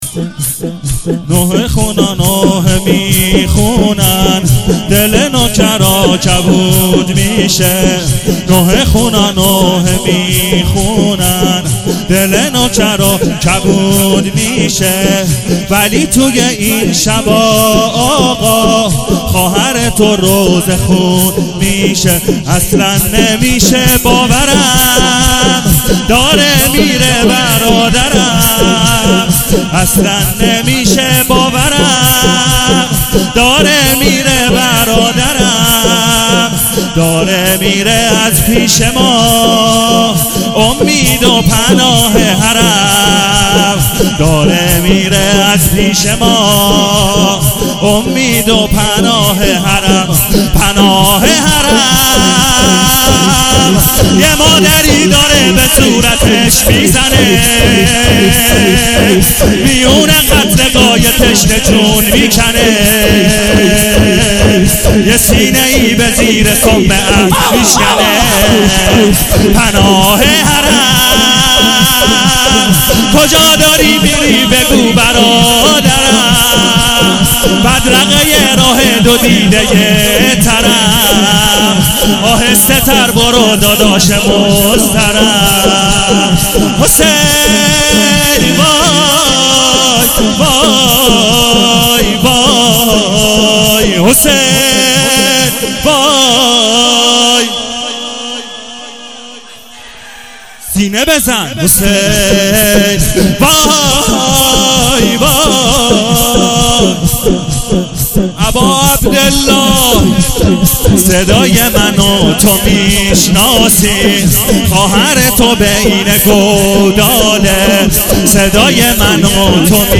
شور-ذاکر مهمان2-شب دوم محرم الحرام1396